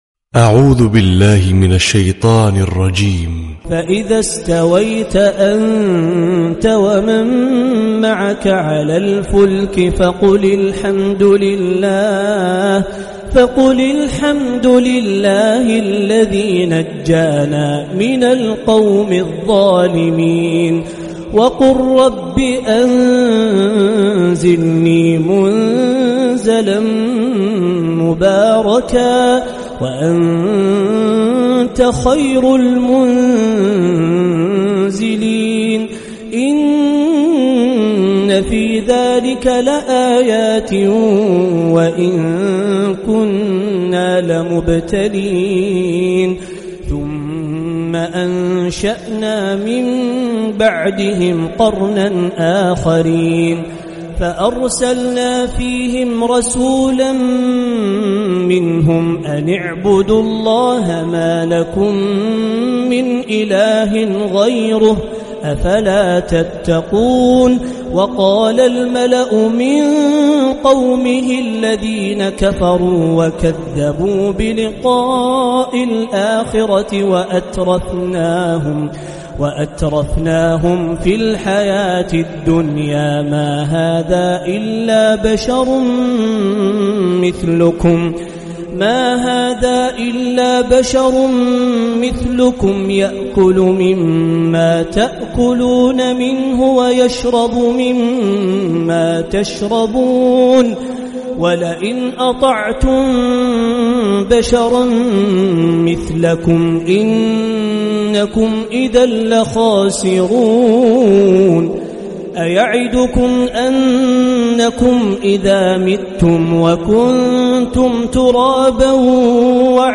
🕋🌻•تلاوة مسائية•🌻🕋